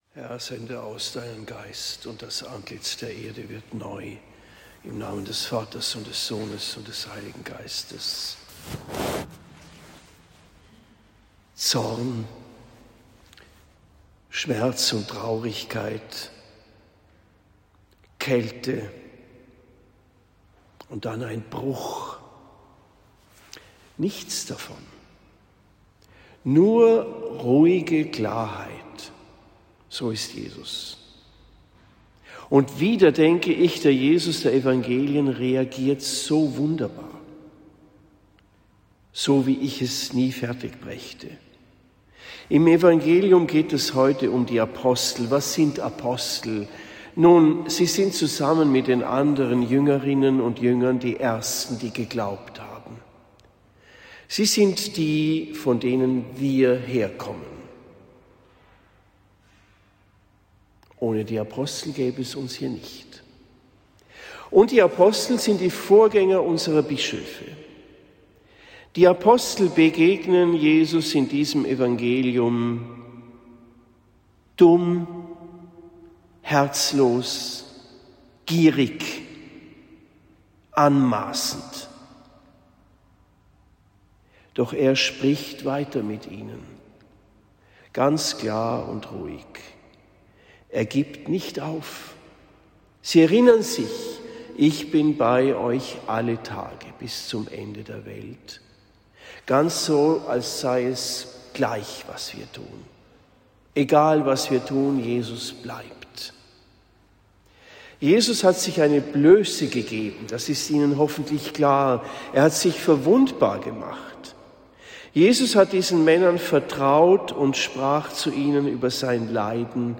Predigt in Marktheidenfeld St.-Laurentius am 03. März 2026